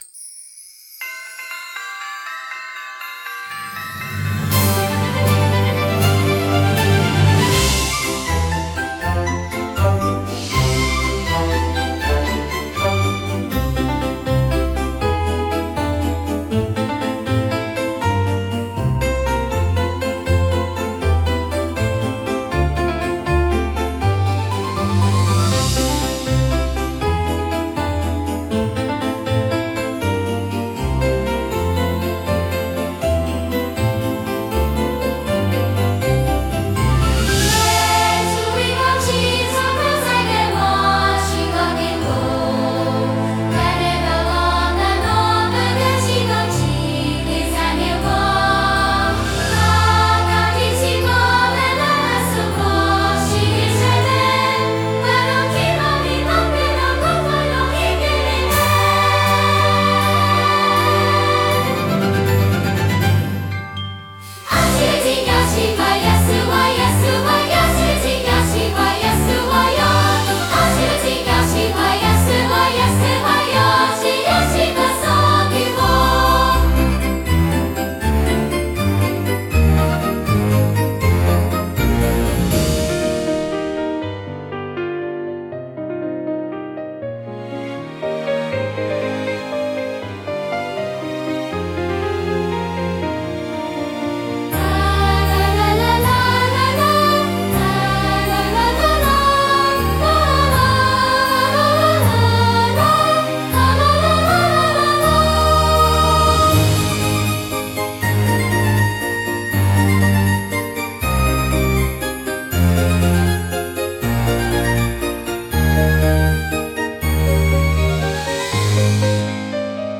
instrumental 8